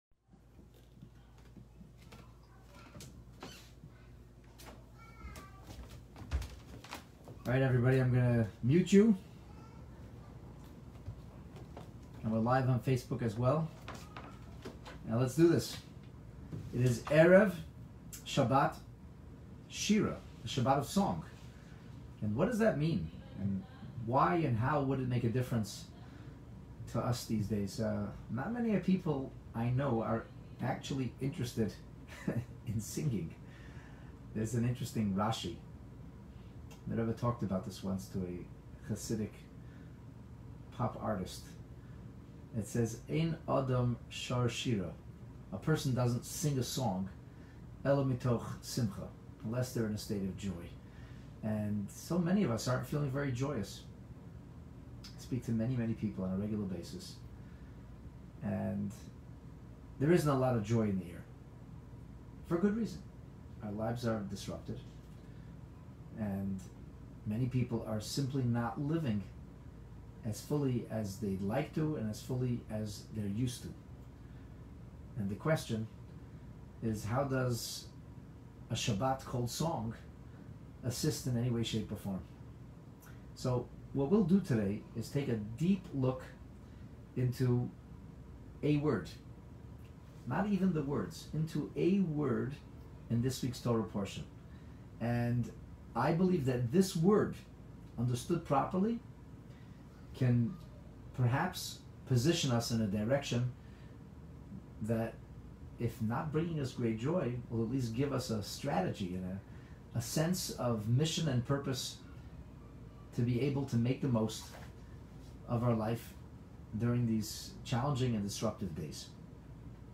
Abandoned Treasures, Shifting Gears And Living In The Moment Pre - Shabbat Shira Sermon